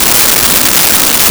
Static Loop 02
Static Loop 02.wav